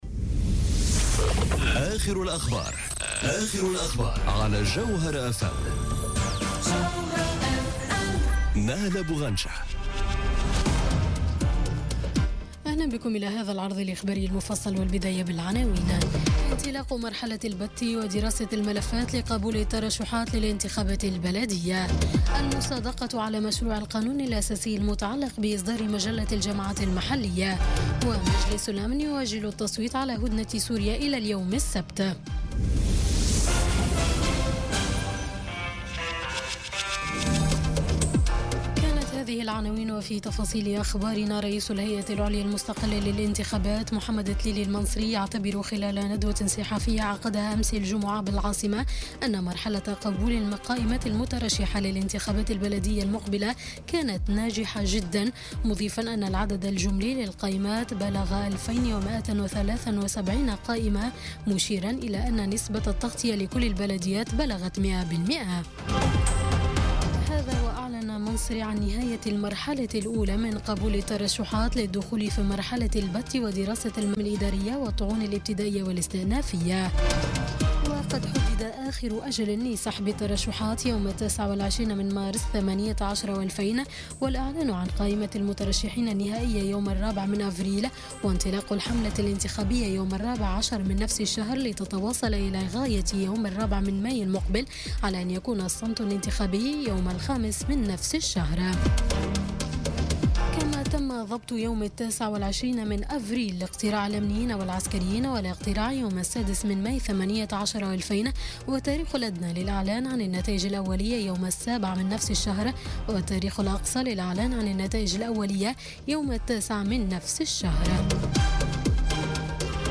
نشرة أخبار منتصف الليل ليوم السبت 24 فيفري 2018